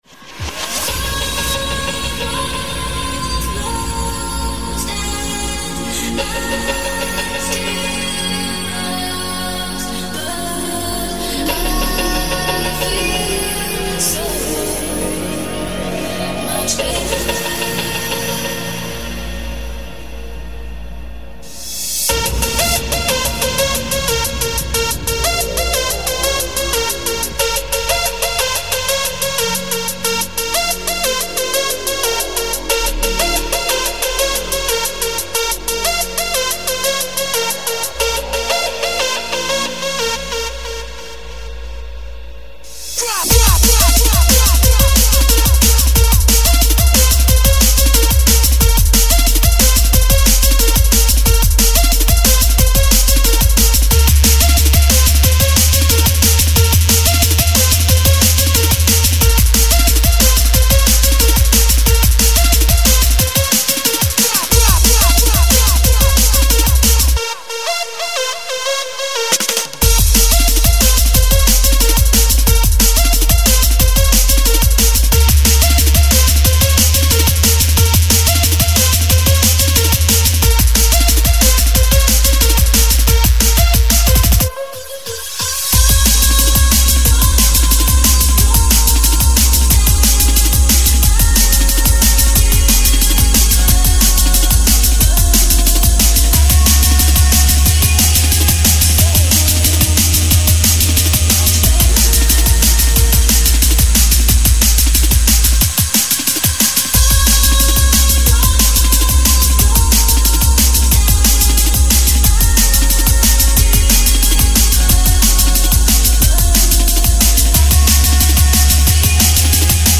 Жанр: Drum & Bass